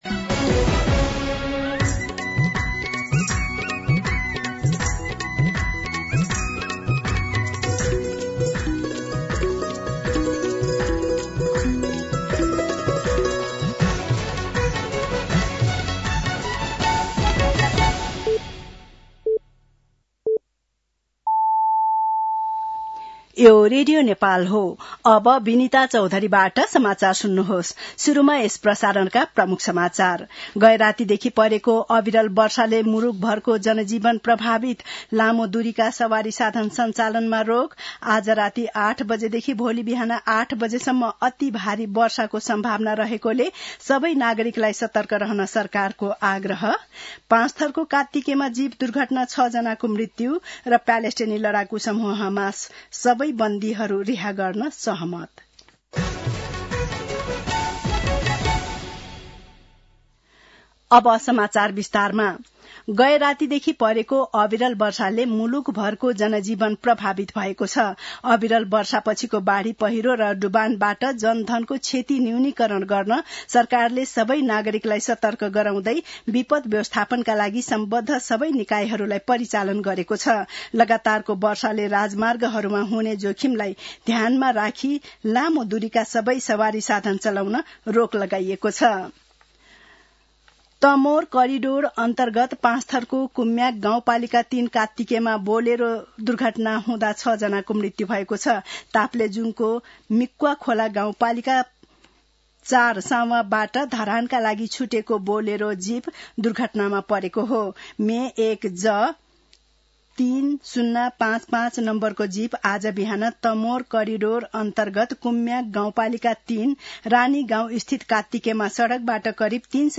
दिउँसो ३ बजेको नेपाली समाचार : १८ असोज , २०८२
3-pm-Nepali-News-1.mp3